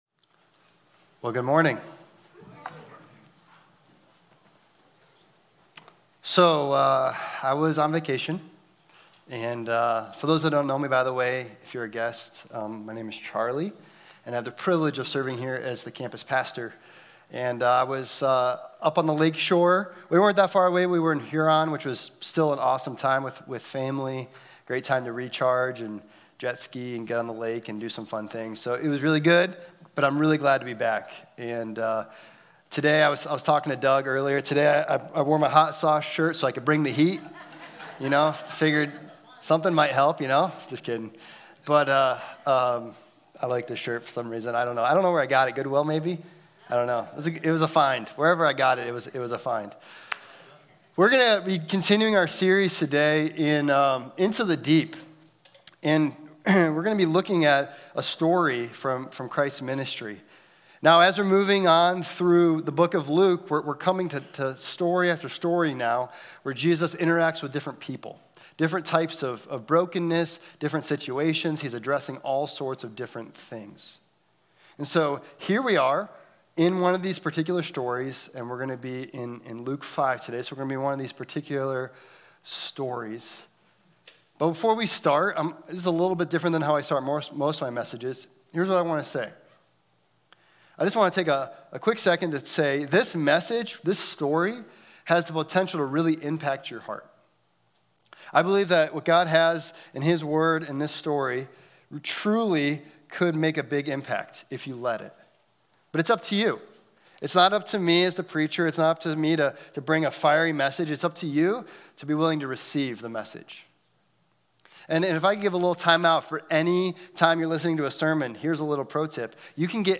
2023 Into the Deep Sunday Morning Into the Deep “Be Clean” Luke 5:12-16 “If you are willing